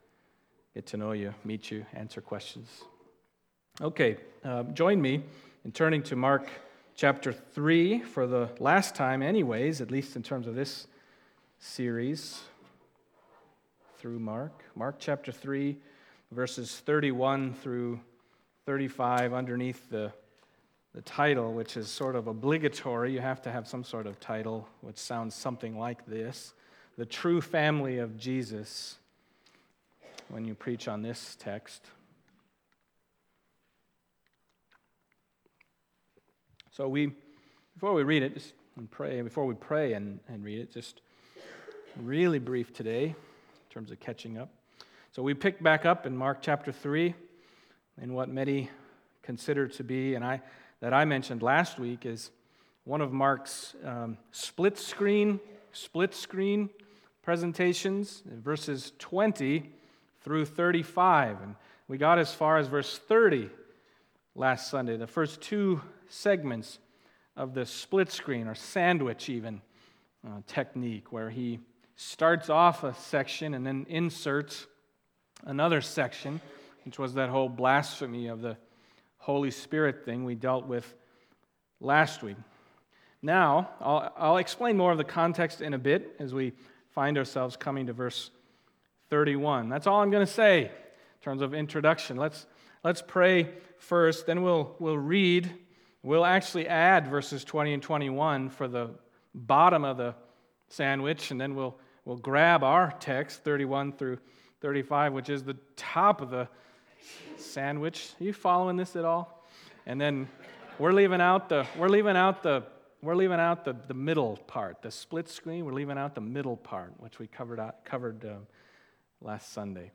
Mark Passage: Mark 3:31-35 Service Type: Sunday Morning Mark 3:31-35 « Jesus